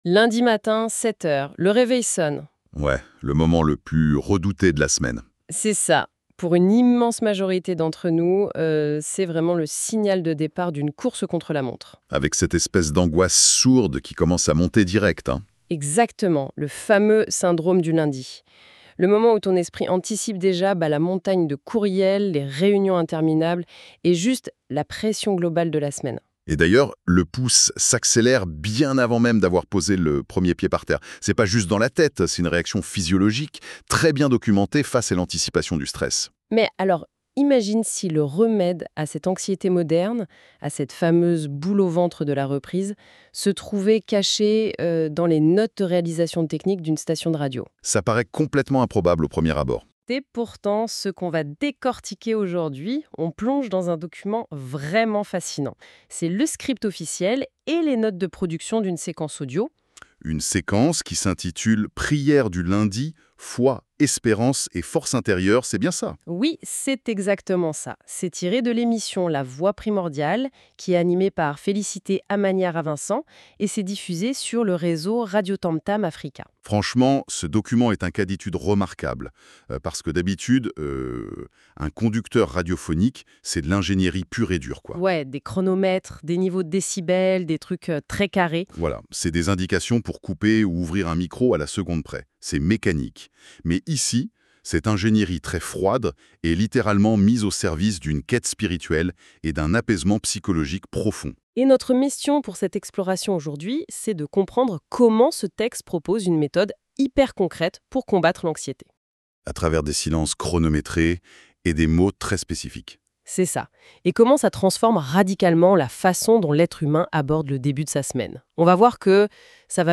Radio TAMTAM AFRICA PRIÈRE DU LUNDI | Foi, Espérance et Force intérieure pour bien commencer la semaine Podcast : La Voix Primordiale 23 mars 2026
– Une introduction méditative pour apaiser l’esprit